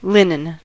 linen: Wikimedia Commons US English Pronunciations
En-us-linen.WAV